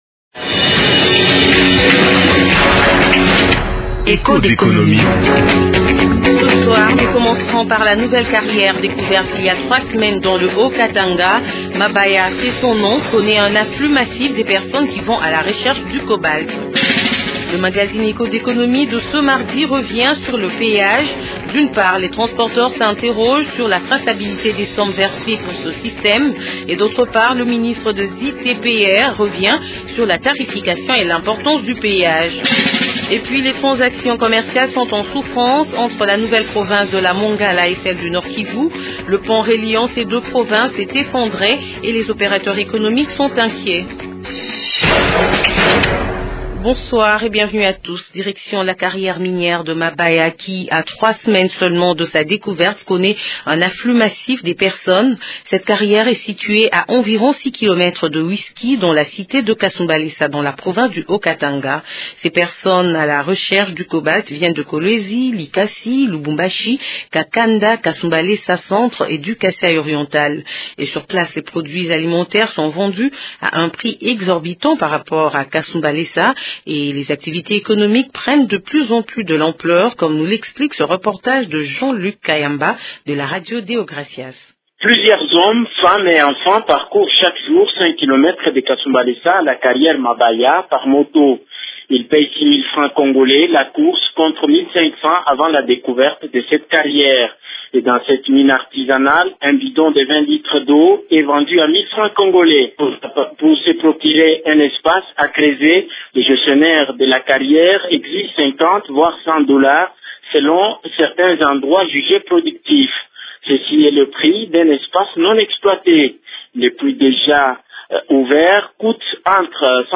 Reportage à suivre dans cette émission: